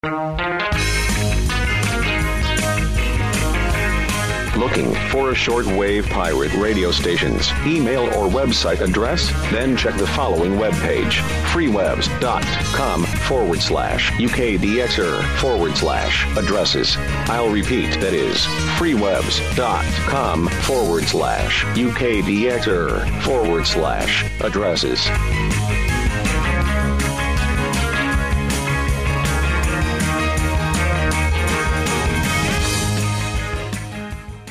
Special Promo for UK-DXer Adddress Site